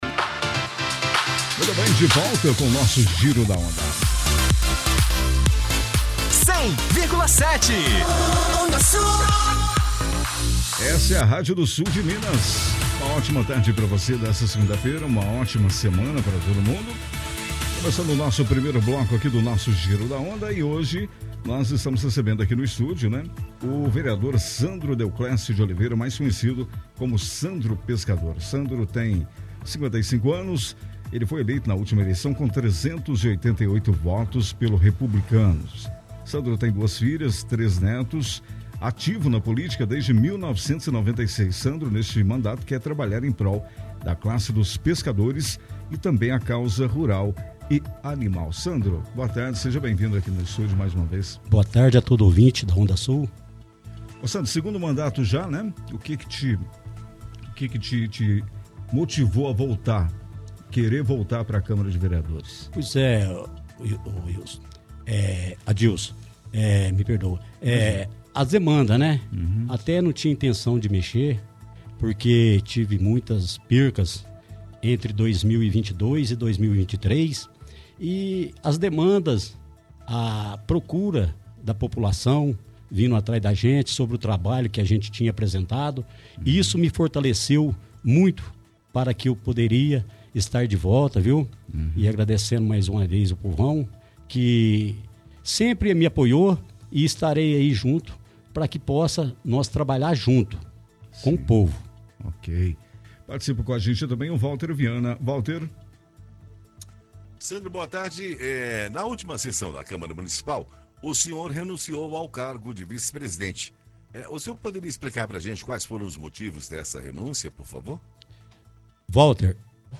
Durante programa “Giro da Onda”, desta segunda-feira(10), o vereador Sandro Pescador, eleito pelo Partido Republicanos com 388 votos, compartilhou seus projetos para o segundo mandato na Câmara de Carmo do Rio Claro.
Entrevsita-Sandro.ogg